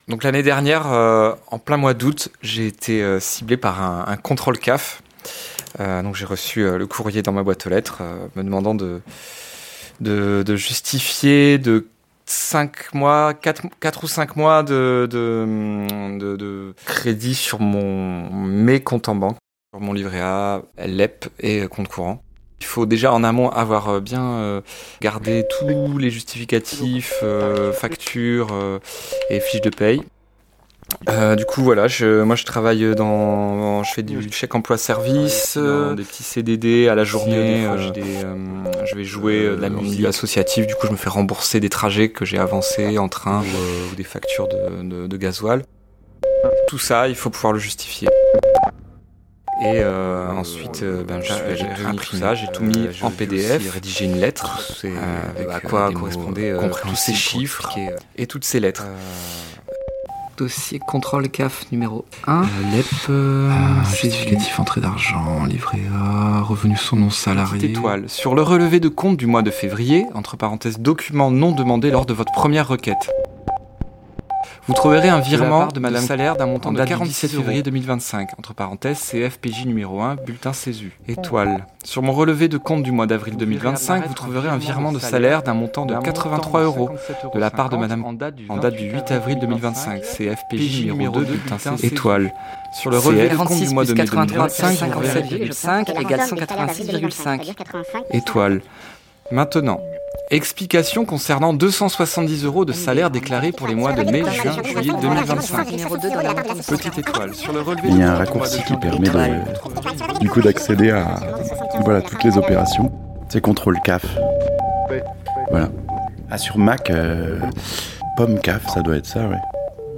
Pendant cette émission on tend le micro à celles et ceux qui misent sur l’entraide, la solidarité et l’action collective pour faire face à cette opération anti-pauvres : la Confédération paysanne du Finistère et l’Assemblée RSA/France Travail de Brest.